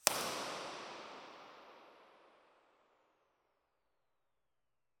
Here are the RIRs for the PL-11.
Test Position 2 – 40 ft
The Tectonic produced significantly more room excitation than the other devices, including the reference Dodecahedron loudspeaker.
Tectonic40ftIR.wav